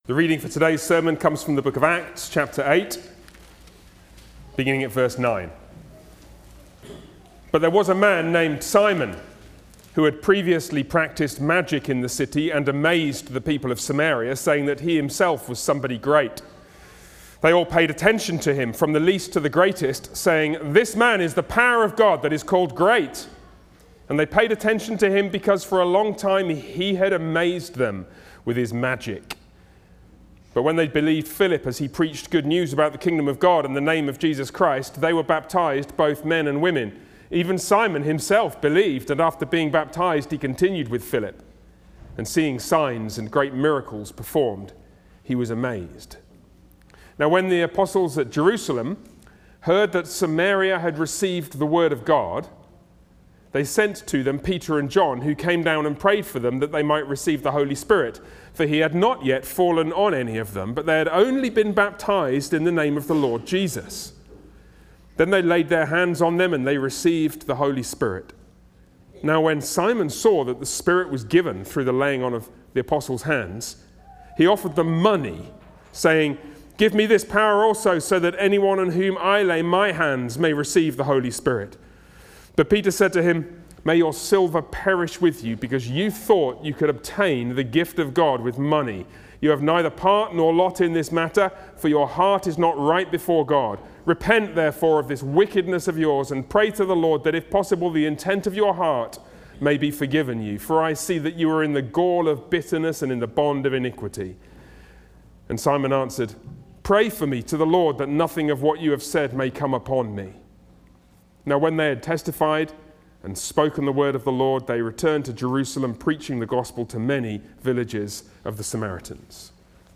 Sermons on Acts
Service Type: Sunday worship